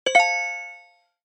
click_2.mp3